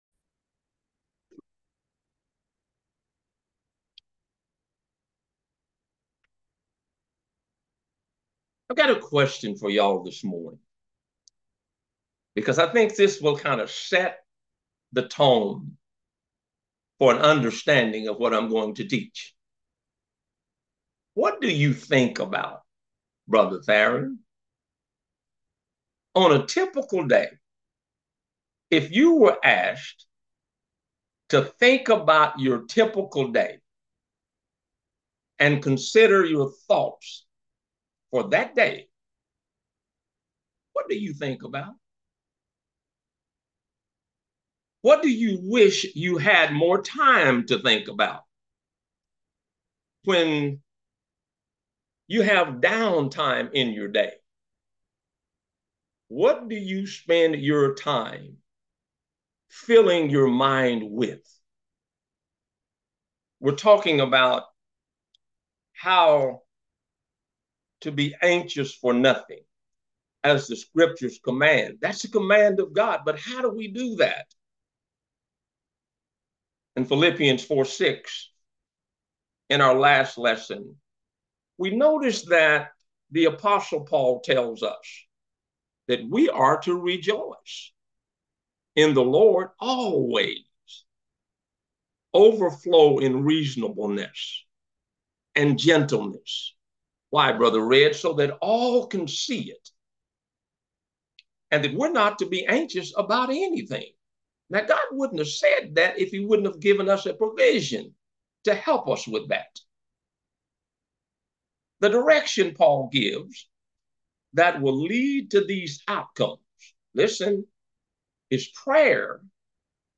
Sunday Worship